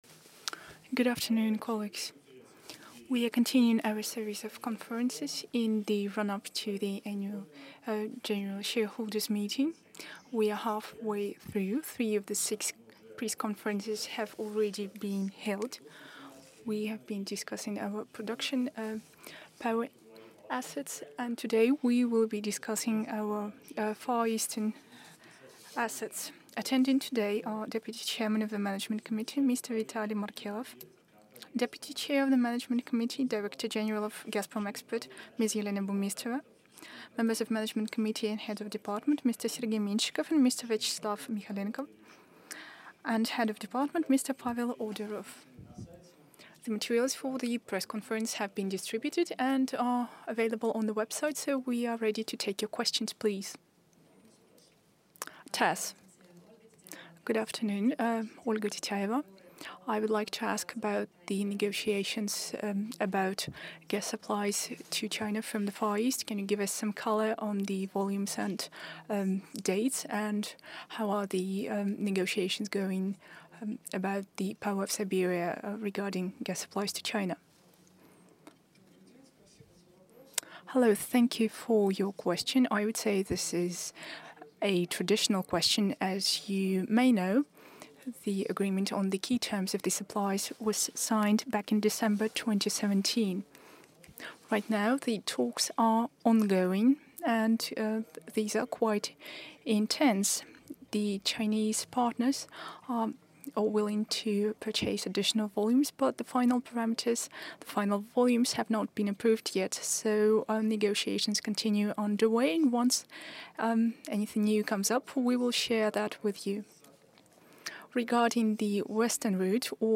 Gazprom in Eastern Russia, Entry into Asia-Pacific Markets Press Conference held – Culture